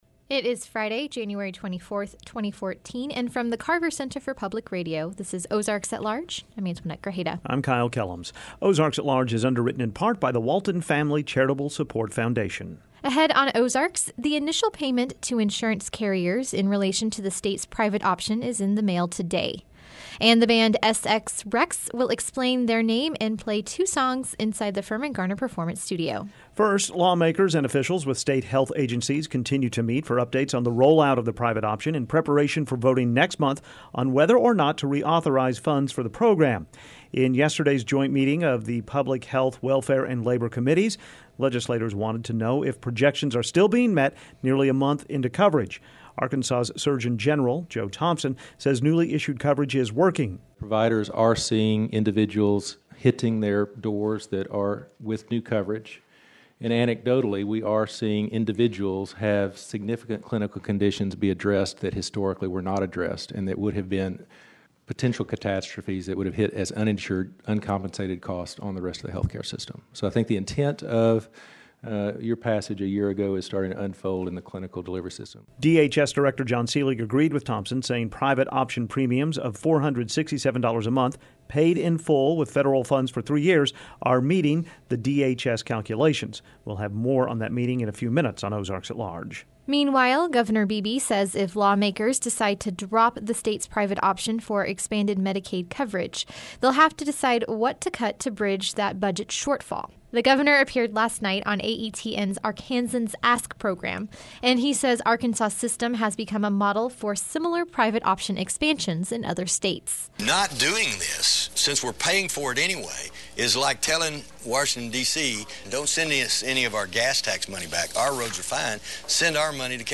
And the band SX Rex will explain their name and play two songs inside the Firmin Garner Performance Studio